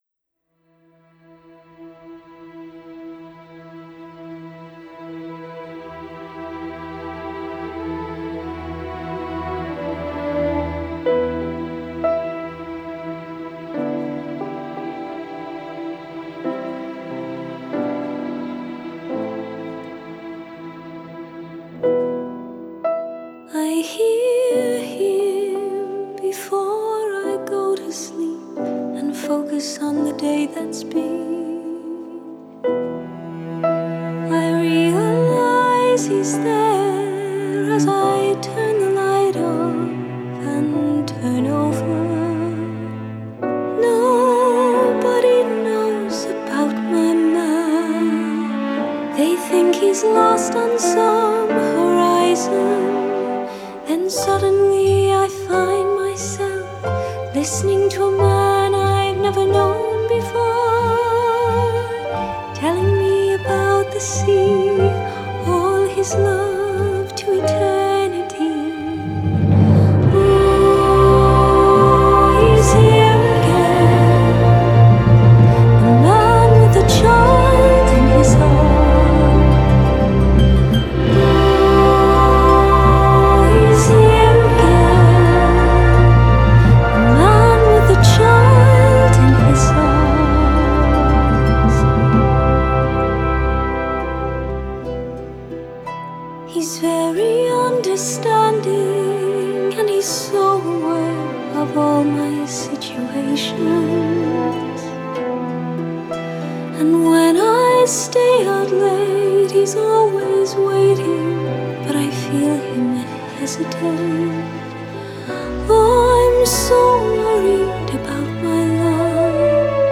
Genre: Classical Crossover, Classical